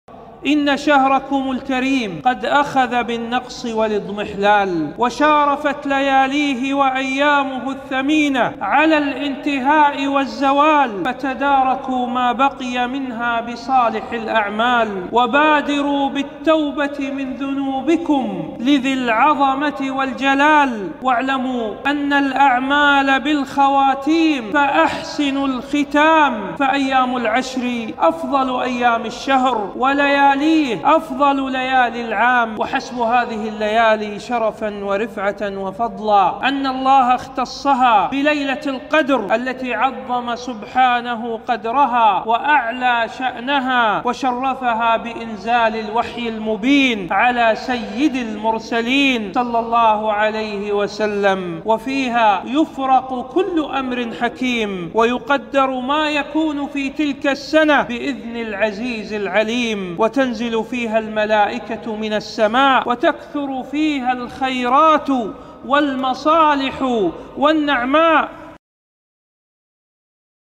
موعظة مؤثرة هذا شهركم الكريم قد أخذ بالنقص والاضمحلال